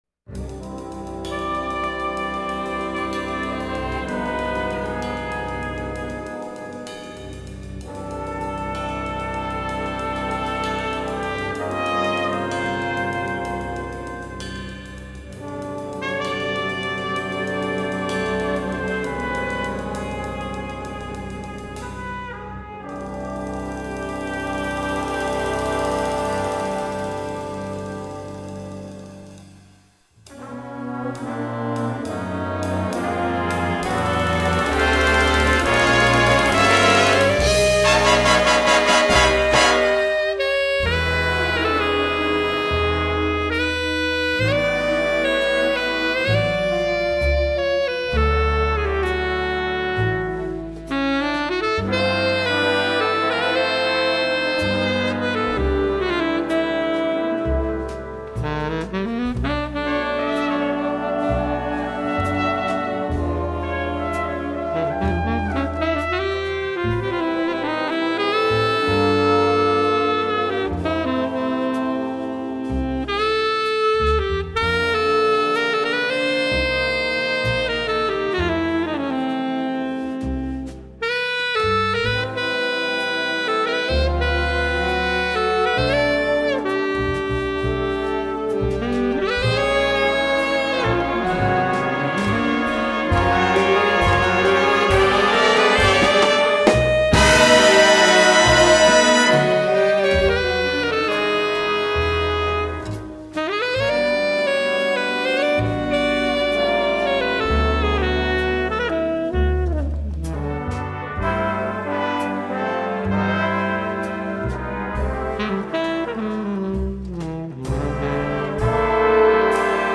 MVHS JAZZ ENSEMBLE PROJECTS BY YEAR
tenor sax?